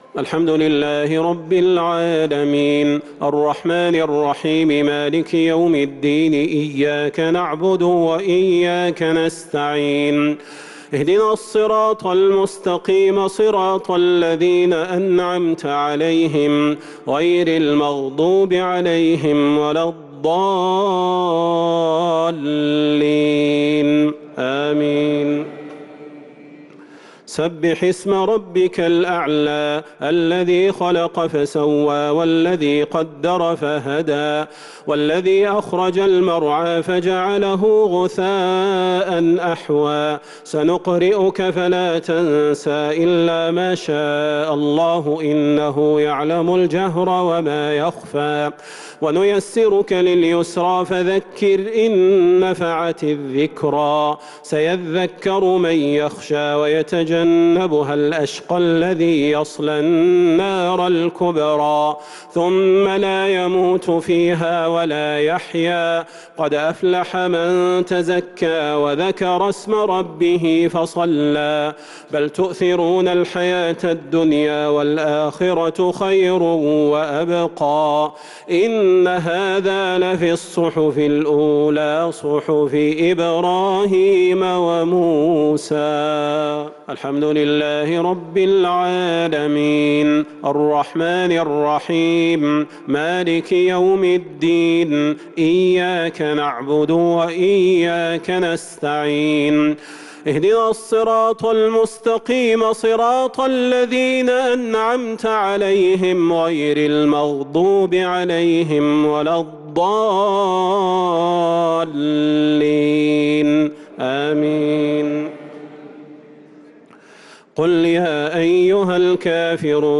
الشفع والوتر ليلة 4 رمضان 1443هـ | Witr 4 st night Ramadan 1443H > تراويح الحرم النبوي عام 1443 🕌 > التراويح - تلاوات الحرمين